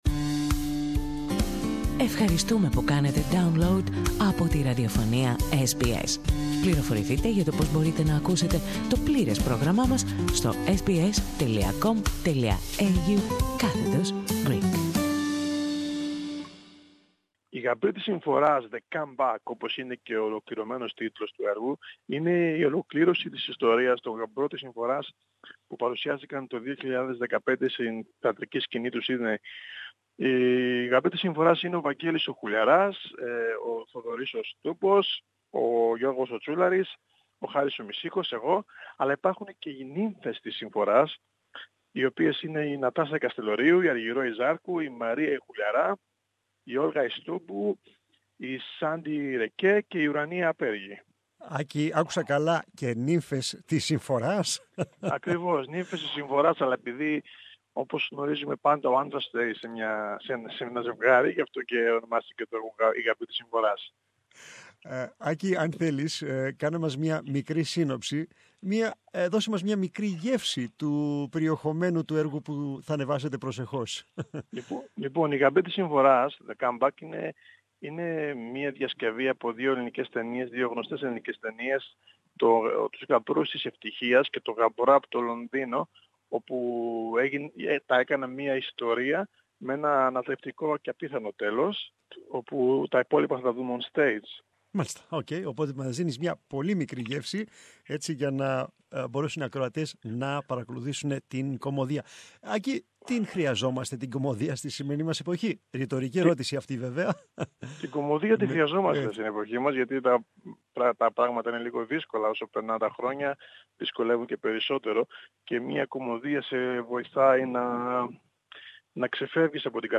Περισσότερα ακούμε στην συνέντευξη